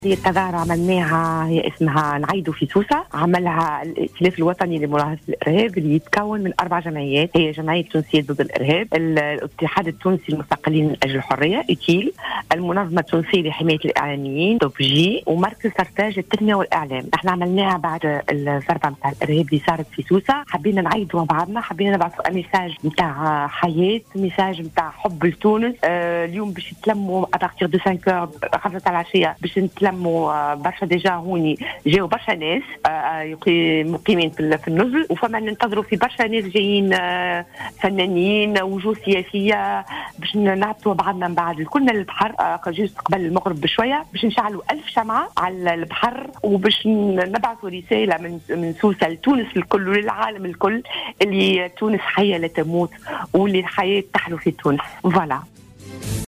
في تصريح ل"جوهرة أف أم"